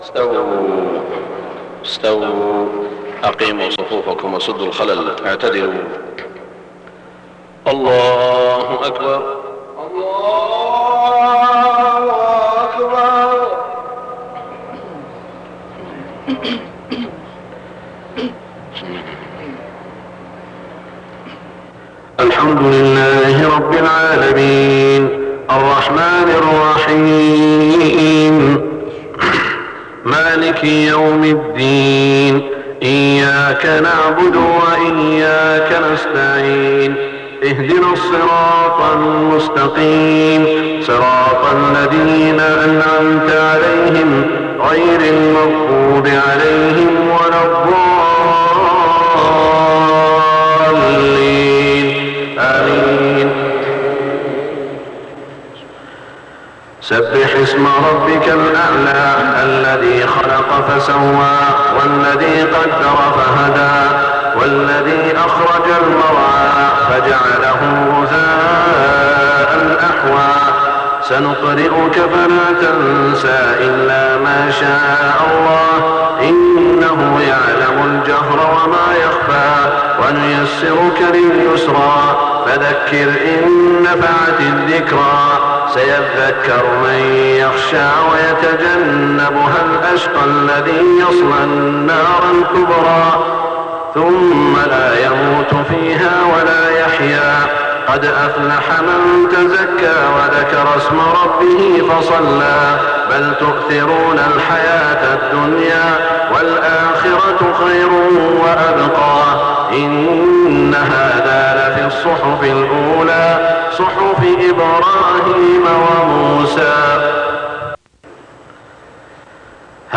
صلاة الجمعة 9-4-1425 سورتي الأعلى و الغاشية > 1425 🕋 > الفروض - تلاوات الحرمين